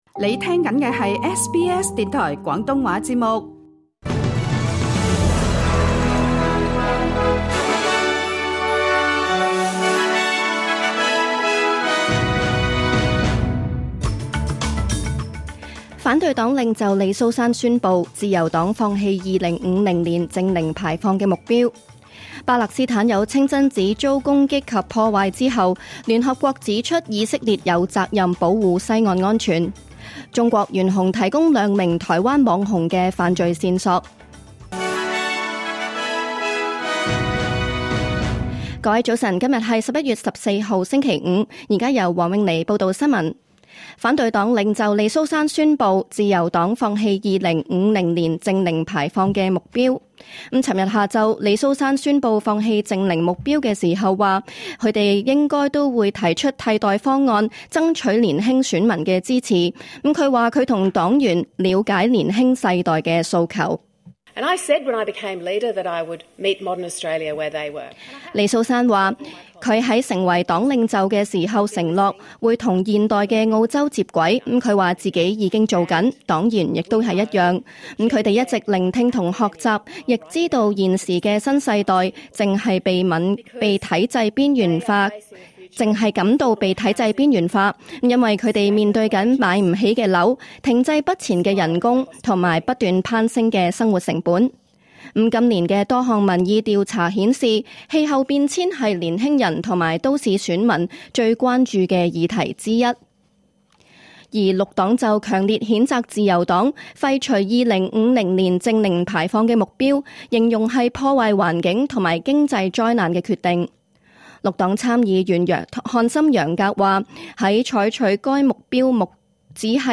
2025年11月14日SBS廣東話節目九點半新聞報道。